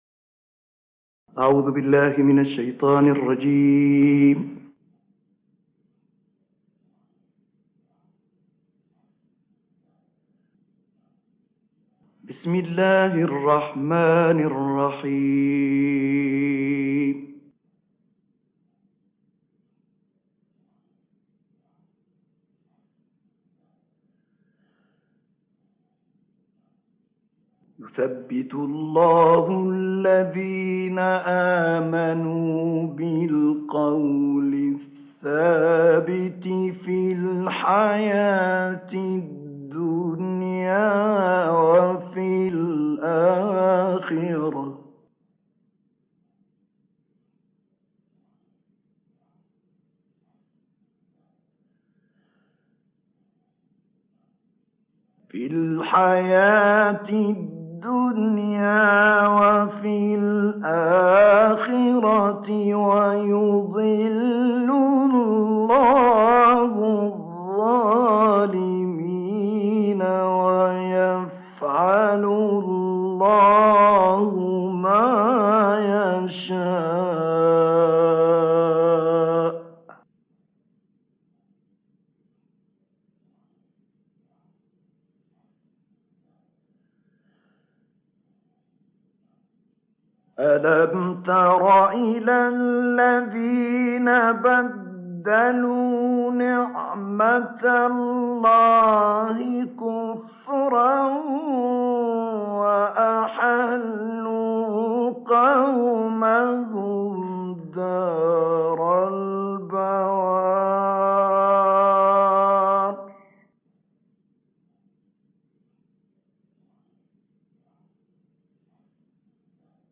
المجموعة الثالثة من تلاوات الشيخ طه الفشنى